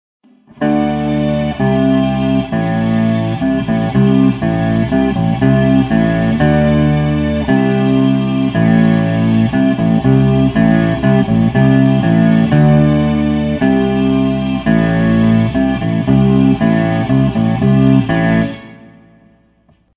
8 string SG Bass
This is an 8 string SG bass in progress.
It is 30" scale.